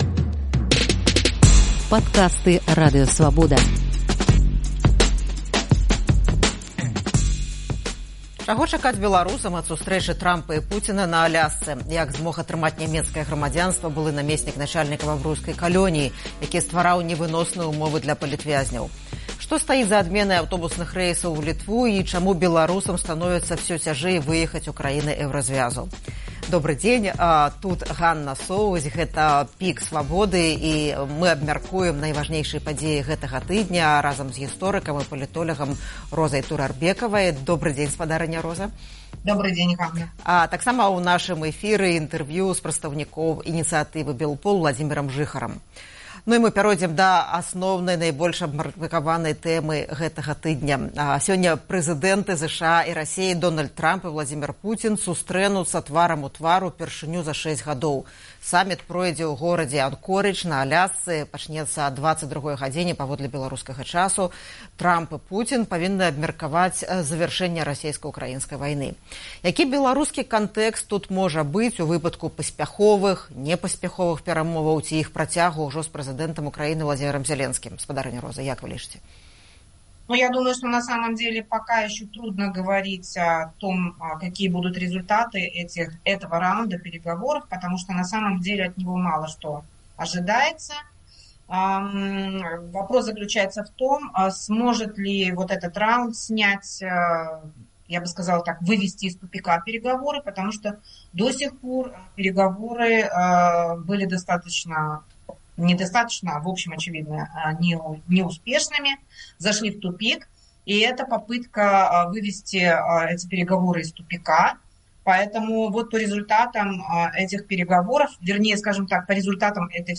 У эфіры «ПіКа Свабоды» абмяркоўваем найважнейшыя падзеі тыдня